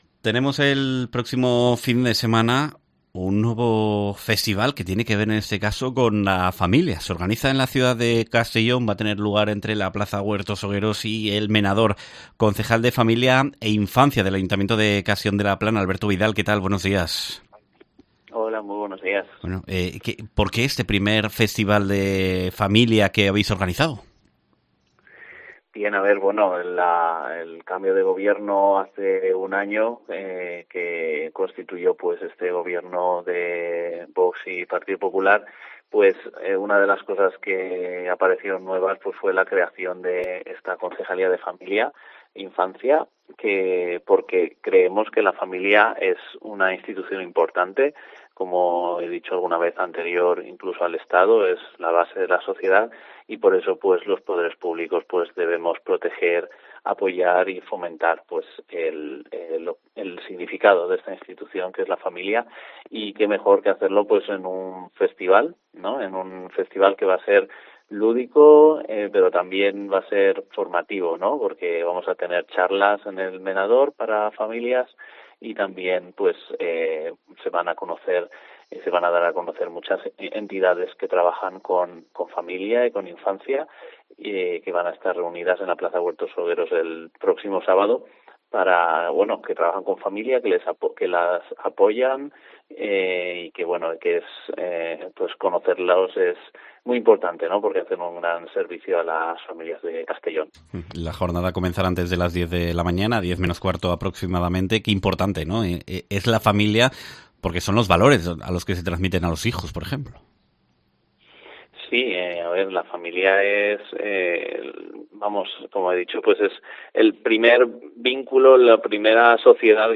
Castellón celebrará el I Festival de la Familia y que presenta en COPE el concejal de Familia e Infancia, Alberto Vidal